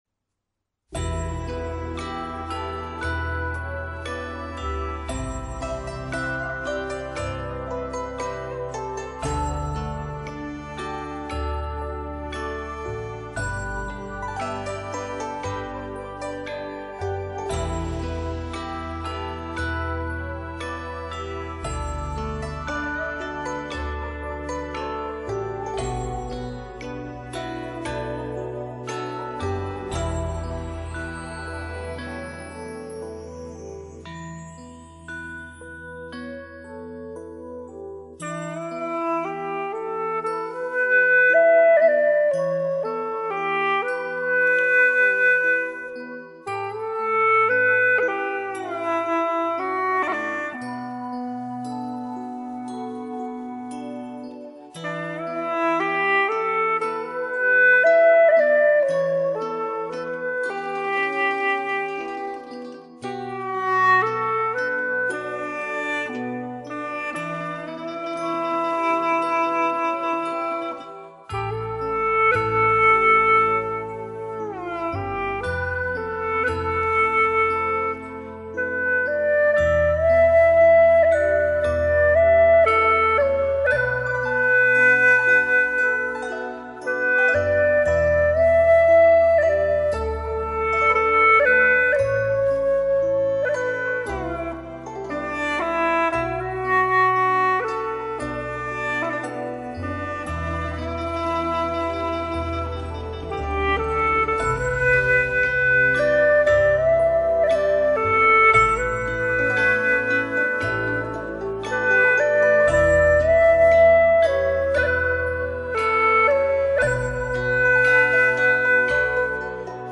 调式 : G 曲类 : 流行